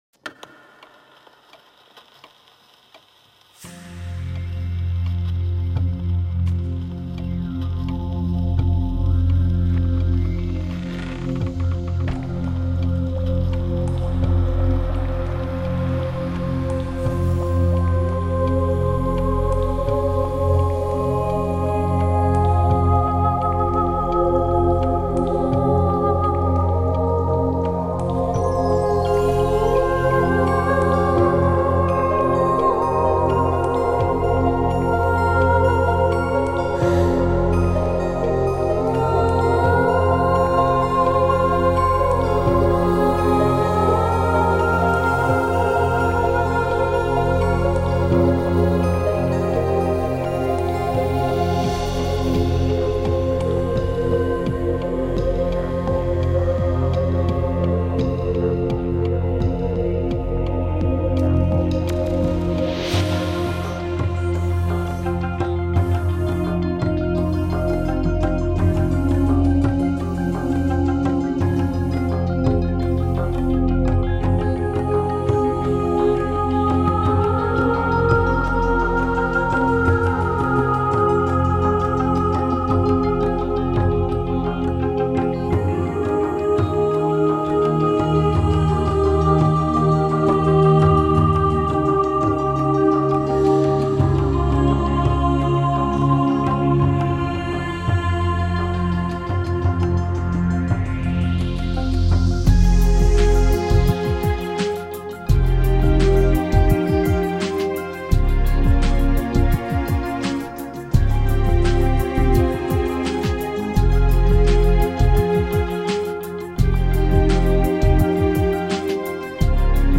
◆音乐类别：精神元素
除此，华丽的电声， 清锵的节奏，空灵的咏叹和舒缓又激荡的旋律一如既往......